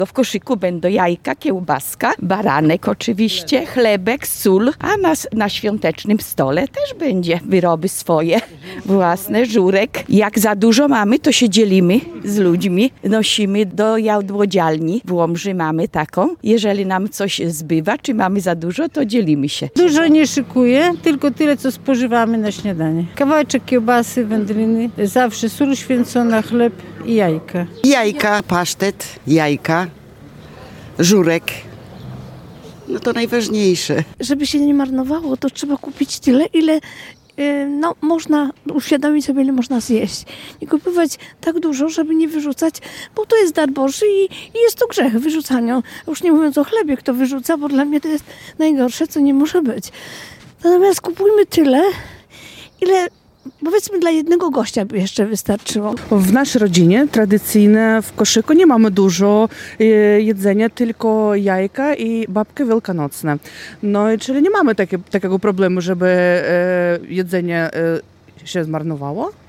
W sklepach i na bazarkach coraz większy ruch, a w wielu domach sprzątanie i planowanie listy świątecznych potrwa. Z mikrofonem Radia Nadzieja sprawdziliśmy, jak mieszkańcy regionu przygotowują się do Wielkanocy.
Więcej w naszej sondzie:
Sonda-Wielkanoc.mp3